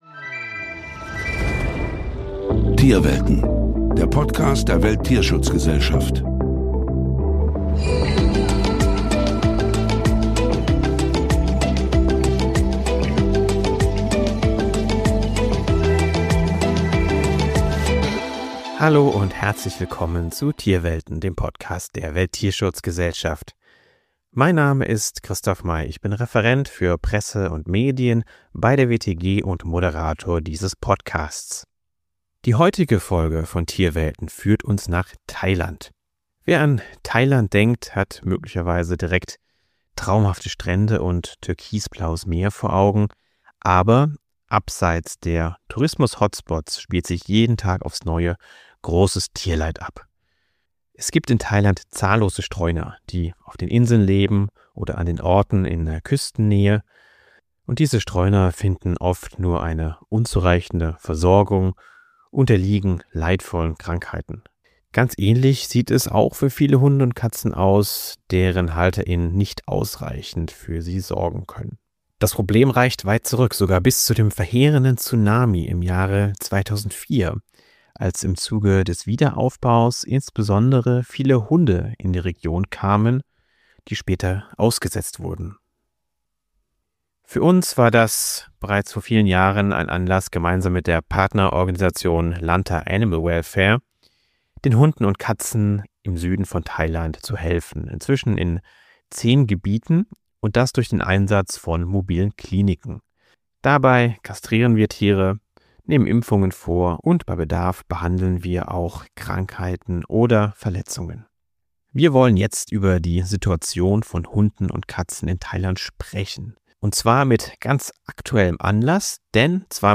Gemeinsam mit unserer Partnerorganisation der Lanta Animal Welfare (LAW) helfen wir Hunden und Katzen in zehn Gebieten im Süden Thailands durch den Einsatz von mobilen Kliniken, in deren Rahmen wir die Tiere kastrieren, Impfungen vornehmen und bei Bedarf auch Krankheiten oder Verletzungen tiermedizinisch behandeln. Aktuelle Eindrücke aus dem Projekt liefern uns die beiden Gesprächspartnerinnen der Folge: